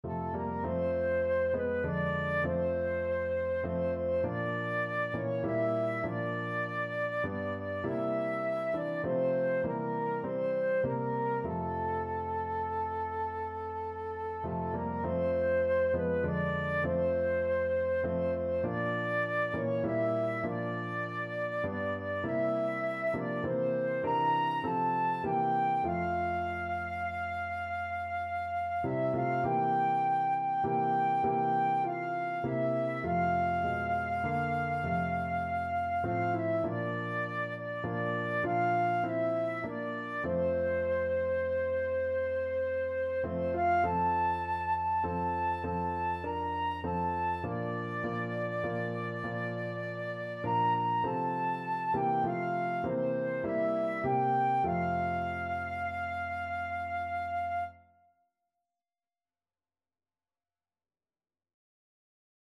Flute version
Christian